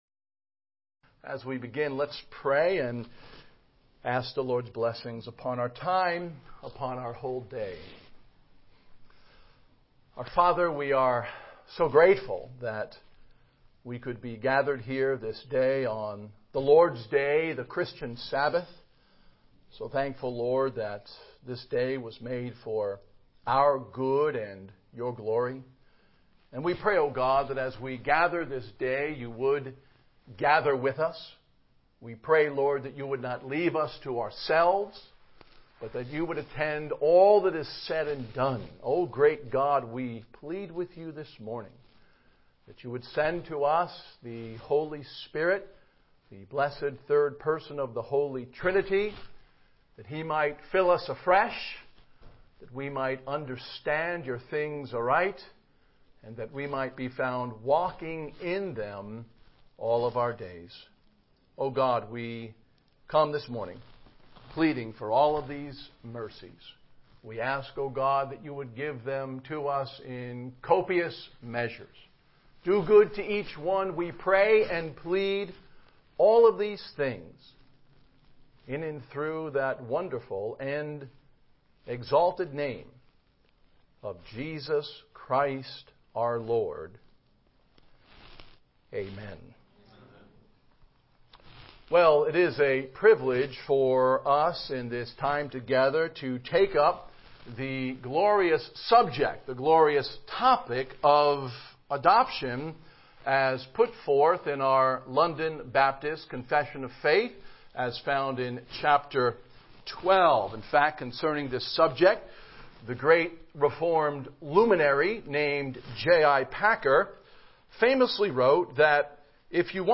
Miscellaneous Passage: Romans 8:15 Service Type: Evening Worship « The Children of God!